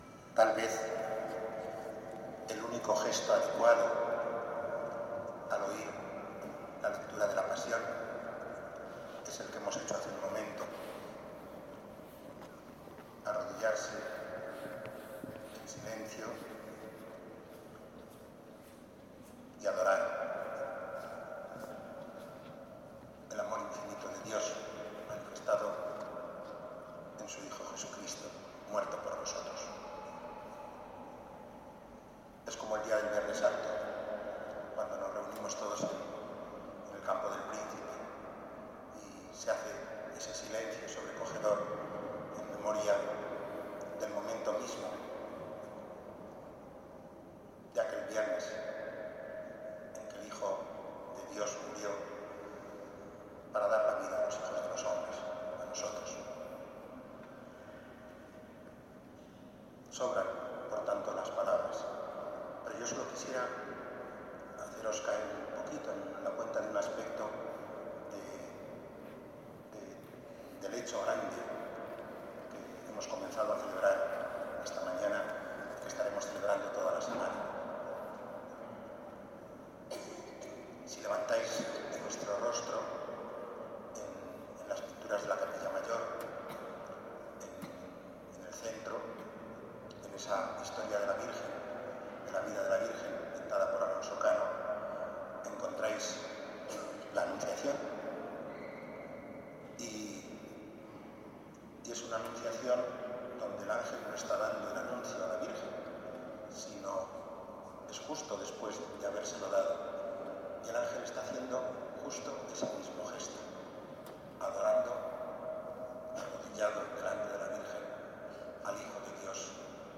Homilía de Mons. Javier Martínez en la Eucaristía del Domingo de Ramos en la S.I Catedral, tras la bendición de ramos y palmas y proclamación del Evangelio en la iglesia de San Andrés, desde donde se llegó a la Catedral en procesión con los fieles.